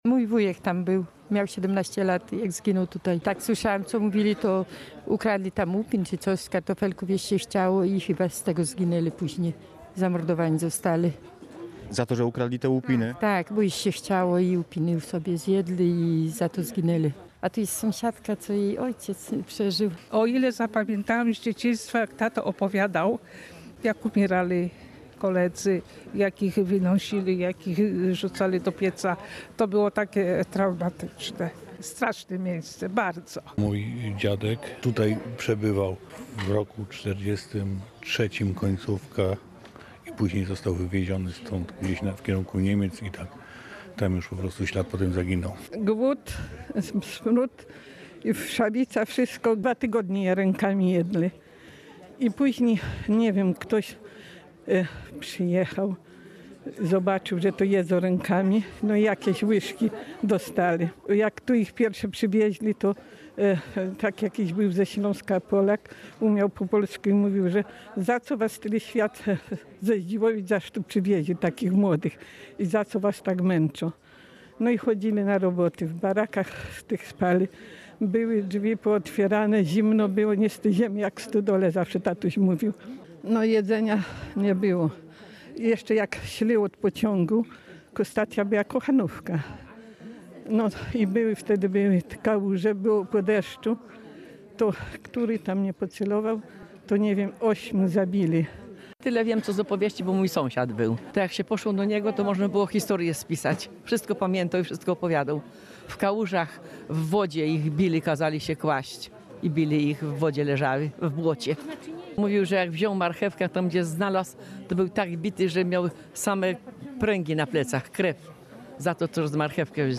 W środę (22.04) hołd pomordowanym oddali okoliczny mieszkańcy, młodzież oraz samorządowcy.